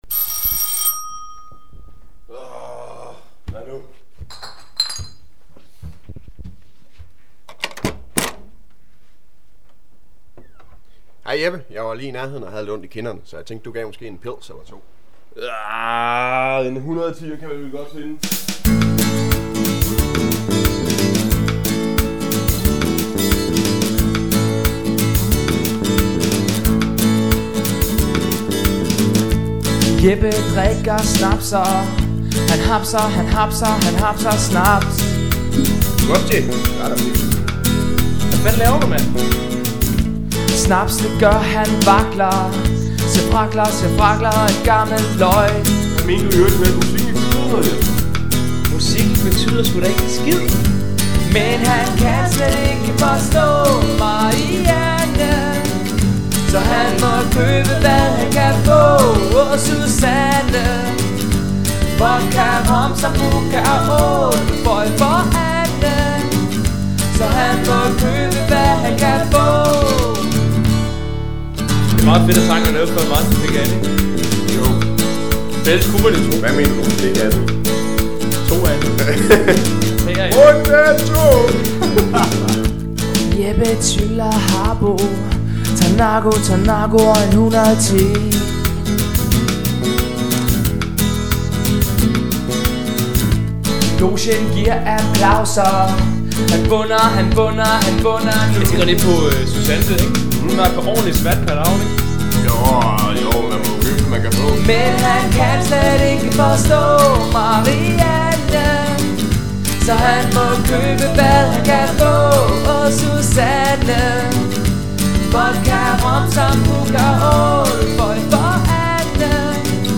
backing vokal
lead vokal